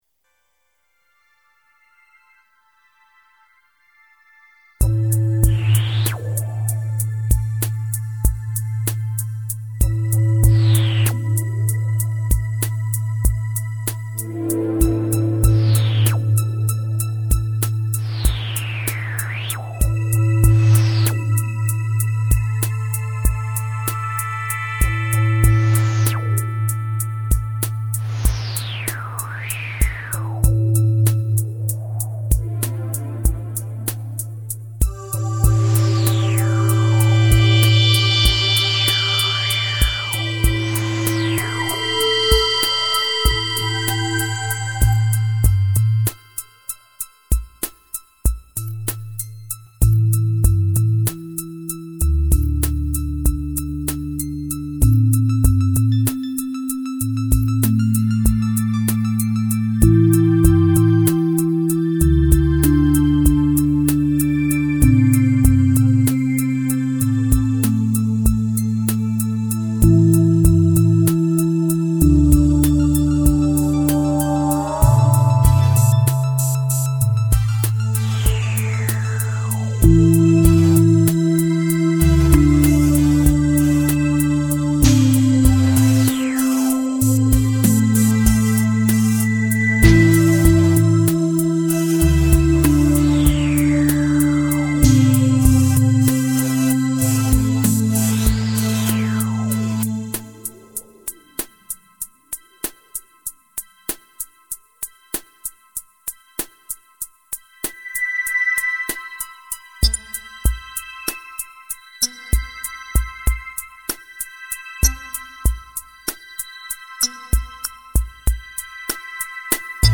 Simple, open sound.